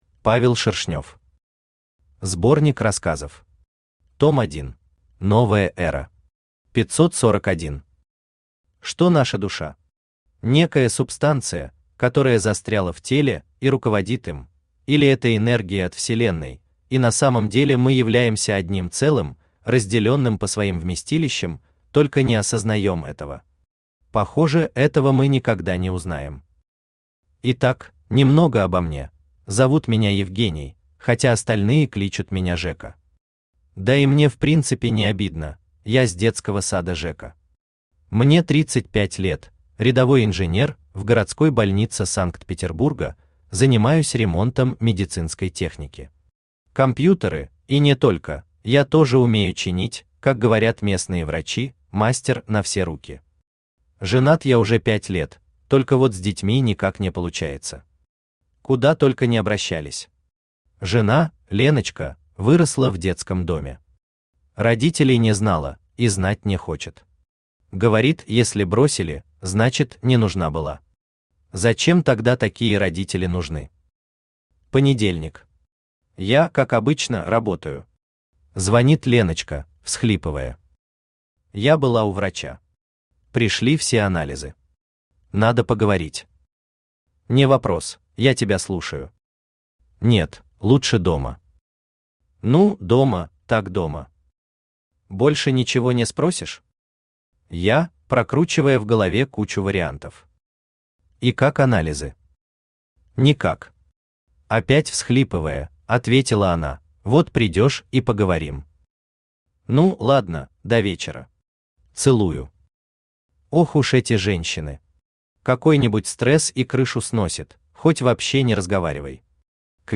Аудиокнига Новая Эра | Библиотека аудиокниг
Aудиокнига Новая Эра Автор Павел Валерьевич Шершнёв Читает аудиокнигу Авточтец ЛитРес.